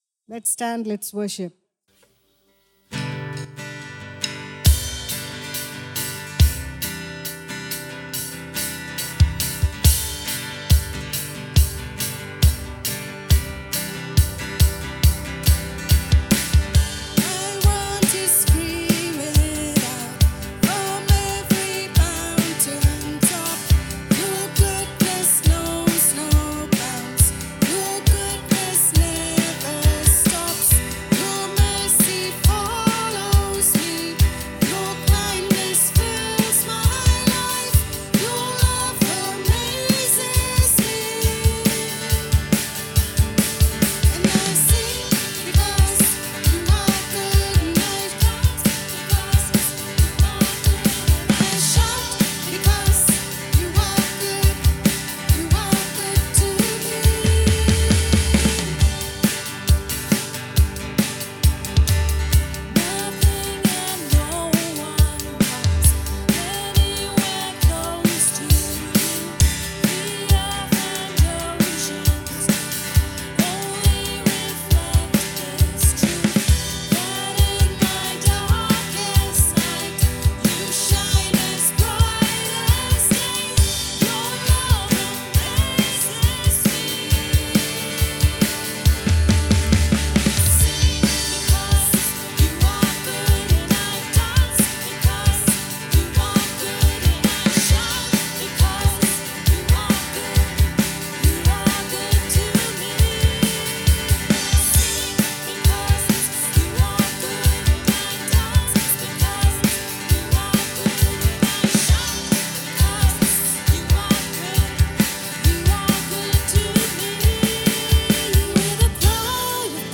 Be blessed as you listen to this live worship at Word of Grace and do write a comment on how you have been blessed by the worship. This is an edited version.